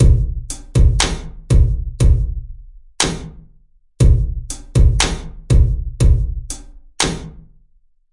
嘻哈节拍120bpm " 节拍120bpm01
Tag: 回路 120BPM 击败 啤酒花 量化 节奏 髋关节 有节奏